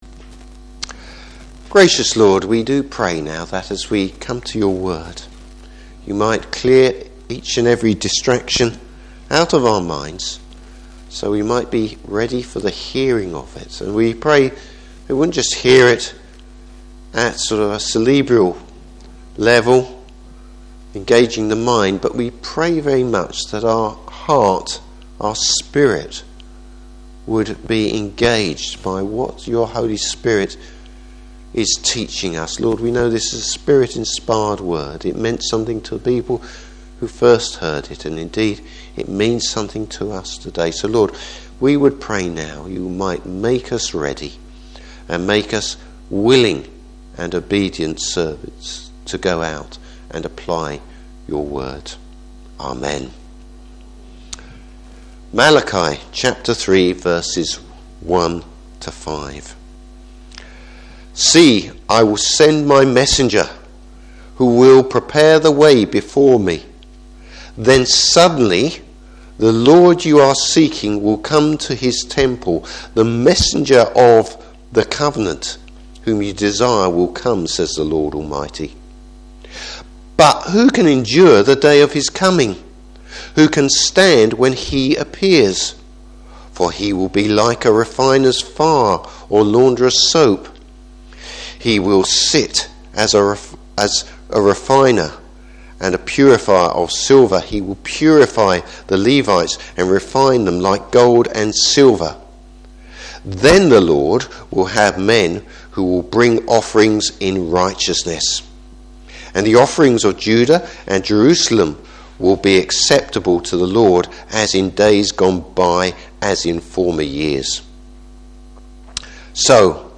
Service Type: Morning Service A wake up call for the Lord’s people!